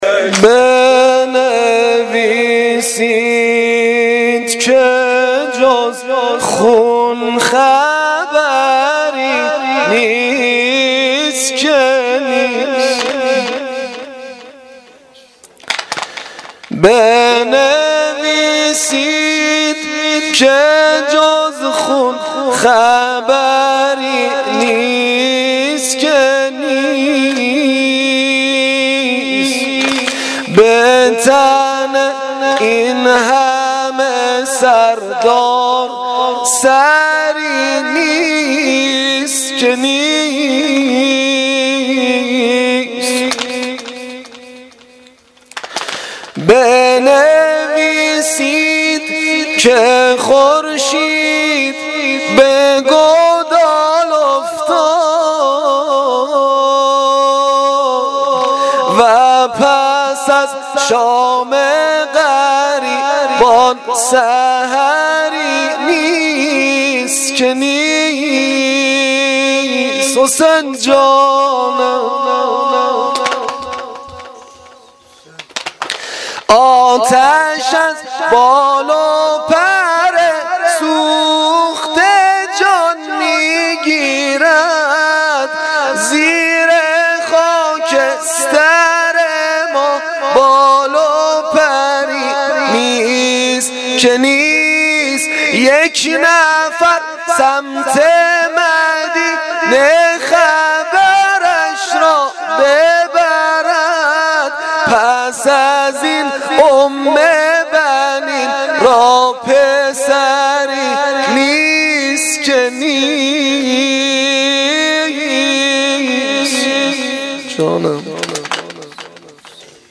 اجرا شده در هیئت جواد الائمه (علیه السلام) کمیجان